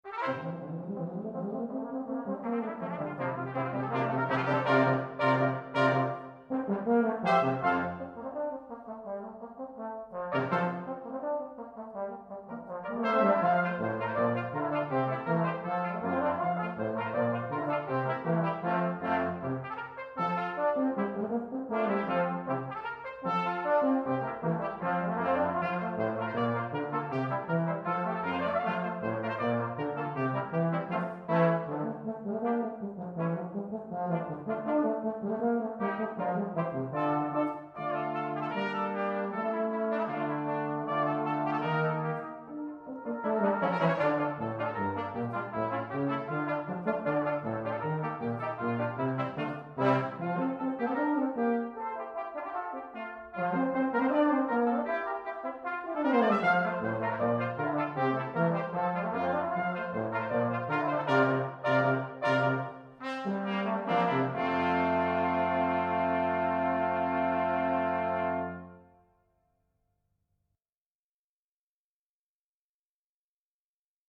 Ensemble 4 voix Flex
Young Band/Jugend Band/Musique de jeunes